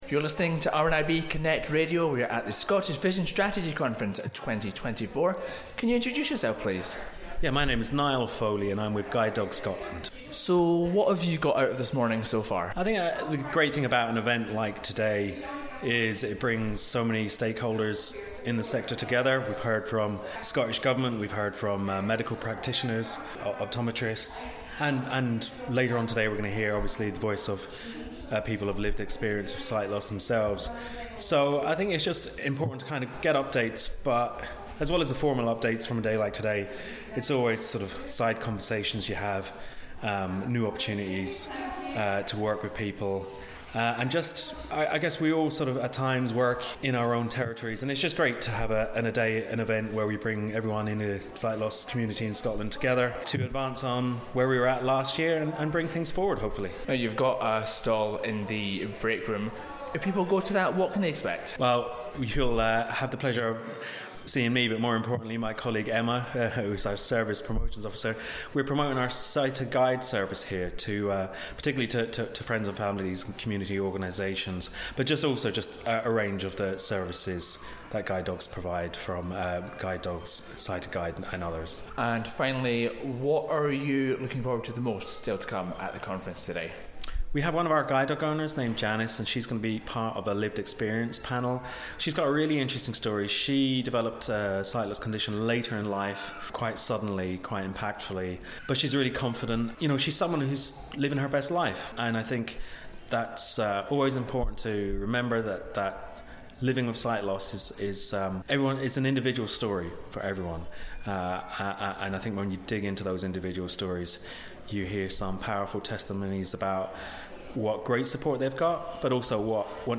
The Scottish Vision Strategy Conference took place on Wednesday 20 March 2024 in Edinburgh.